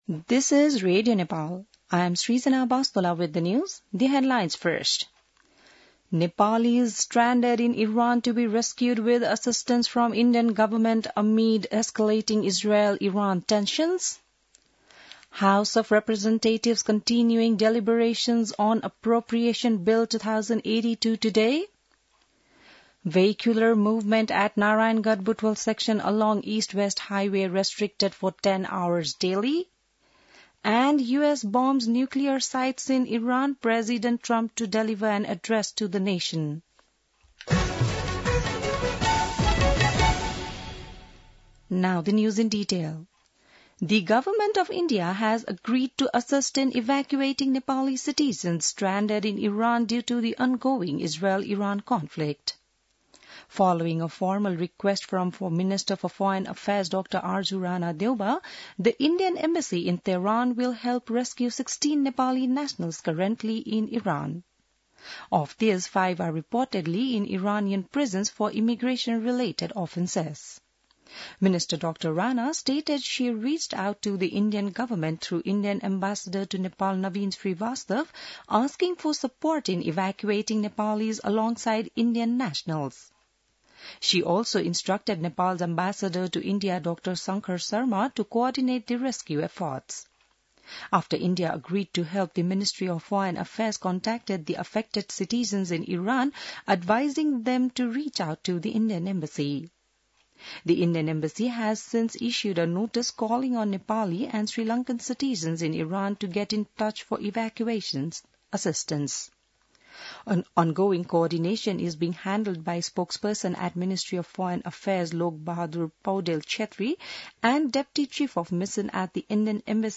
बिहान ८ बजेको अङ्ग्रेजी समाचार : ८ असार , २०८२